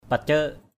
/pa-cə:ʔ/